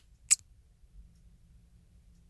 Цыкает монитор при выключении
Появился на днях такой звук при выключении питания монитора.